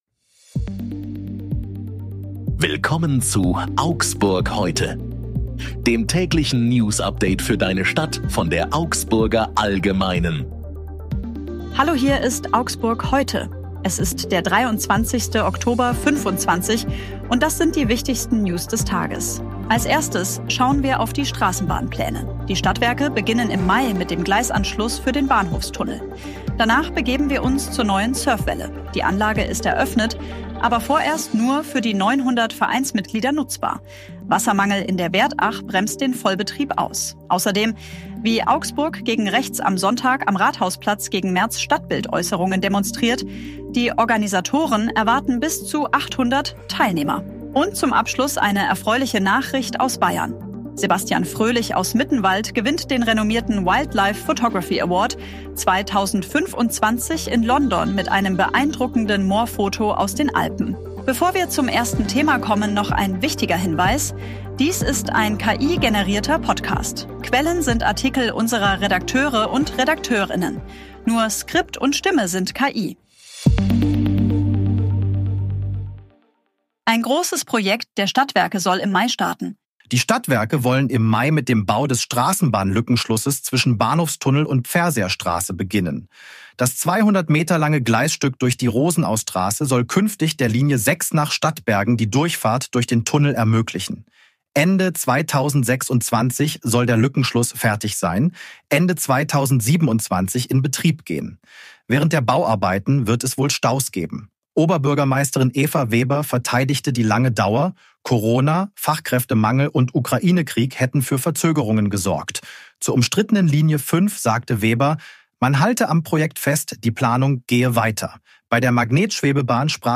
Hier ist wieder das tägliche Newsupdate für deine Stadt.
Nur Skript und Stimme sind KI.